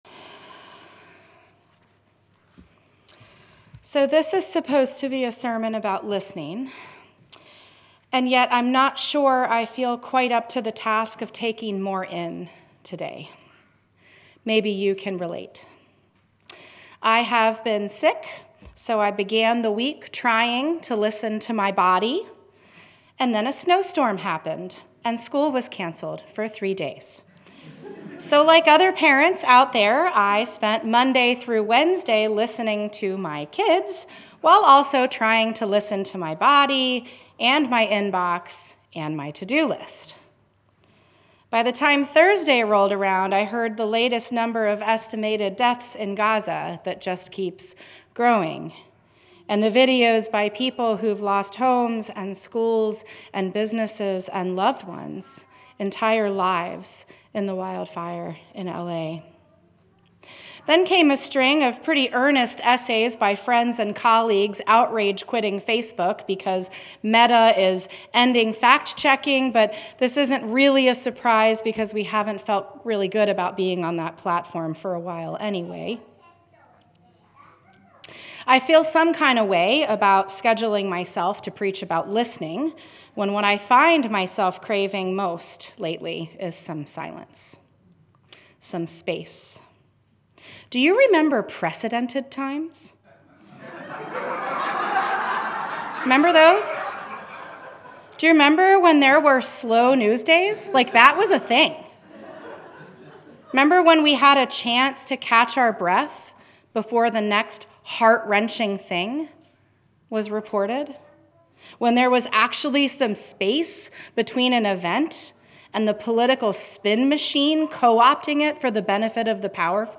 This is a sermon about listening.